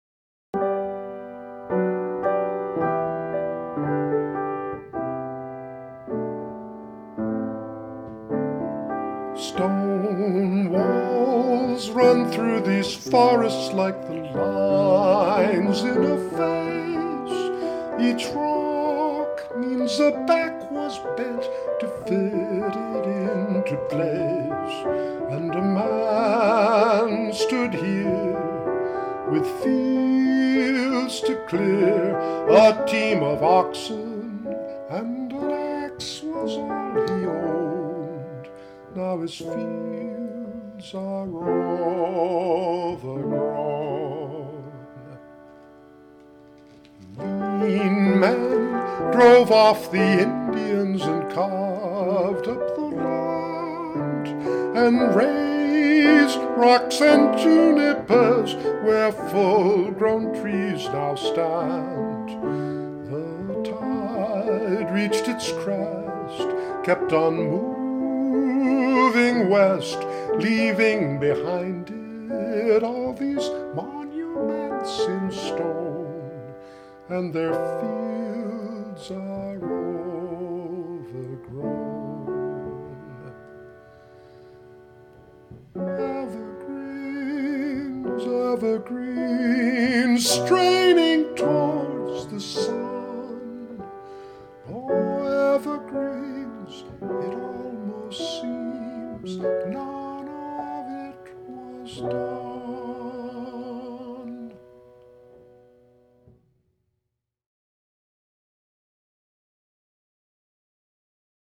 performed by the Manchester Choral Society, May ’07*,